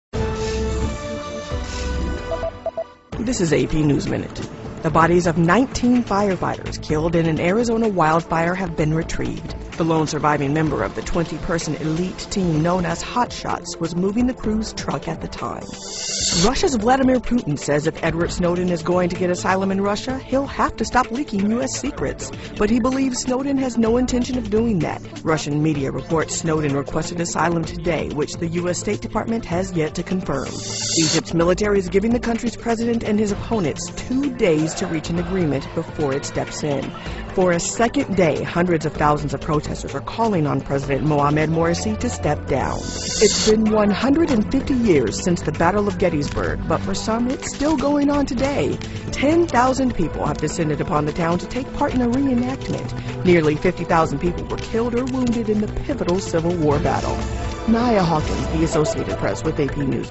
在线英语听力室美联社新闻一分钟 AP 2013-07-05的听力文件下载,美联社新闻一分钟2013,英语听力,英语新闻,英语MP3 由美联社编辑的一分钟国际电视新闻，报道每天发生的重大国际事件。电视新闻片长一分钟，一般包括五个小段，简明扼要，语言规范，便于大家快速了解世界大事。